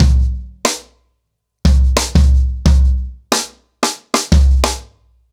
Wireless-90BPM.29.wav